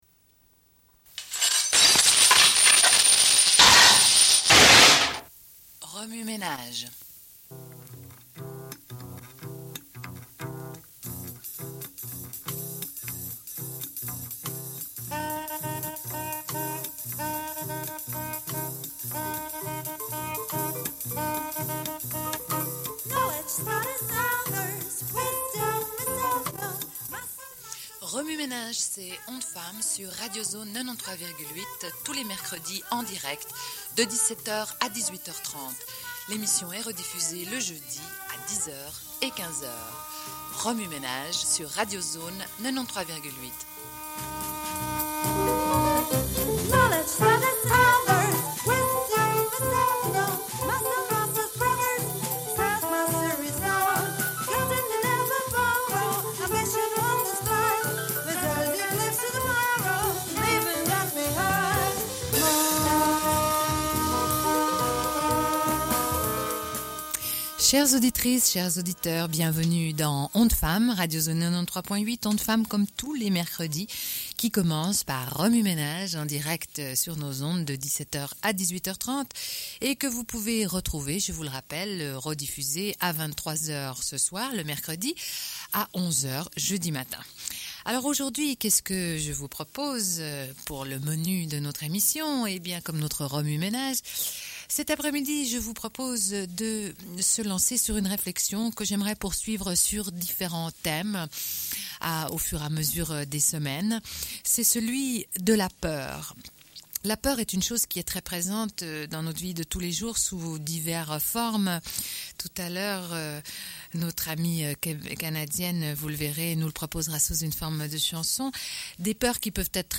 Une cassette audio, face A00:31:01
Radio Enregistrement sonore